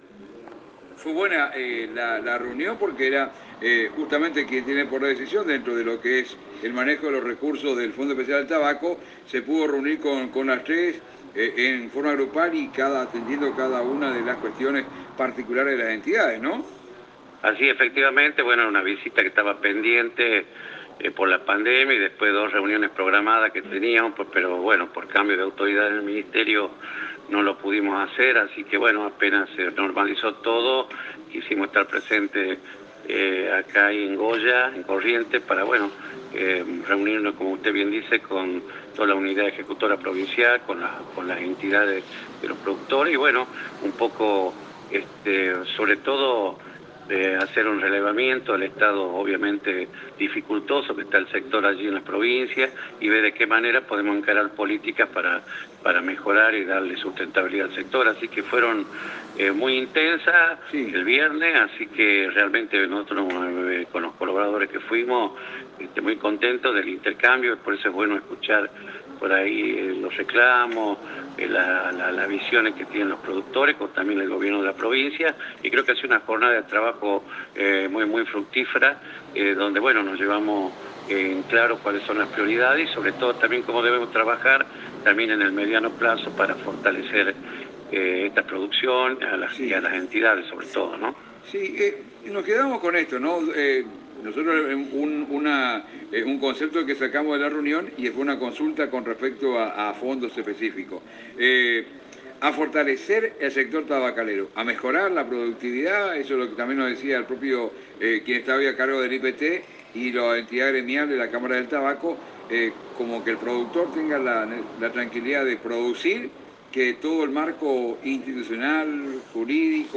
Audio de la entrevista.